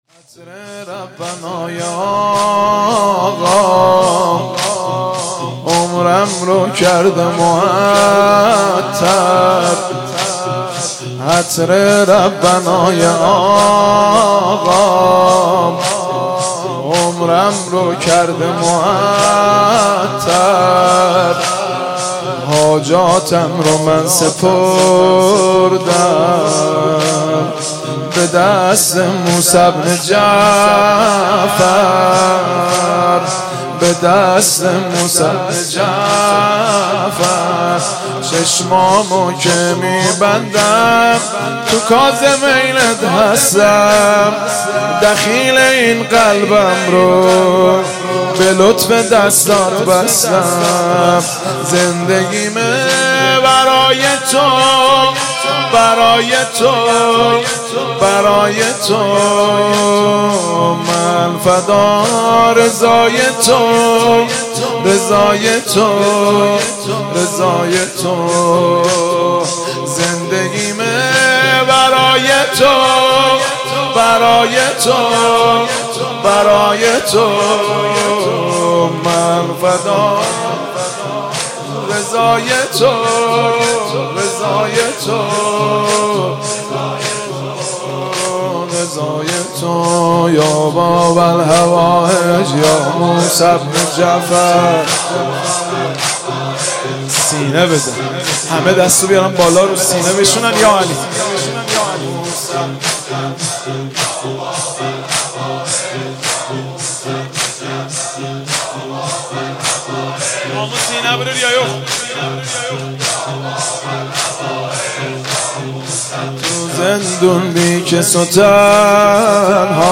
مداحی زمینه
با نوای دلنشین